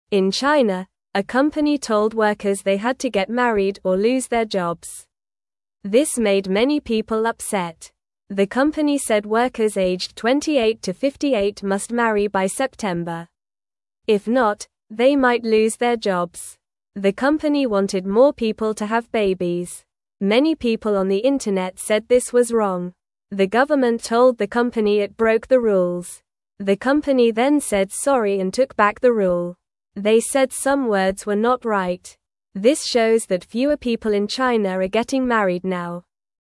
Normal
English-Newsroom-Beginner-NORMAL-Reading-Company-Makes-Workers-Marry-or-Lose-Their-Jobs.mp3